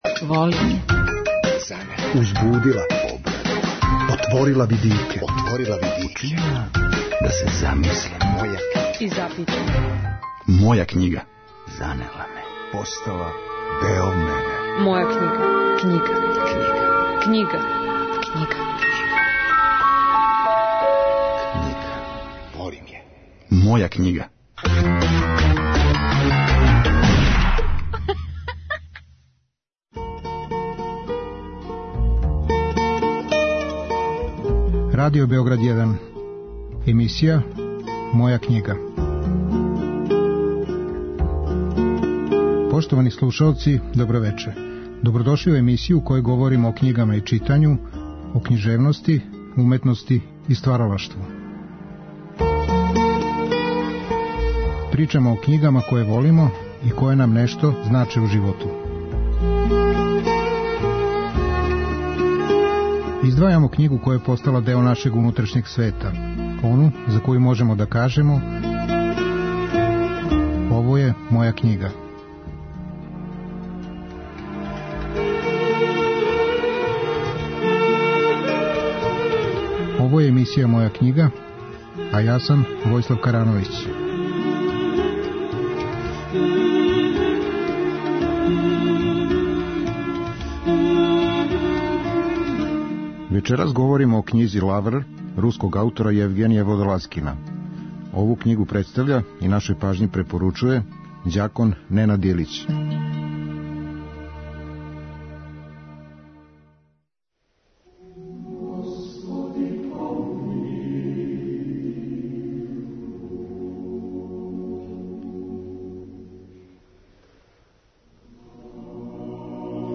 Емисија о књигама и читању, о књижевности, уметности и стваралаштву. Гости су људи различитих интересовања, различитих занимања и професија.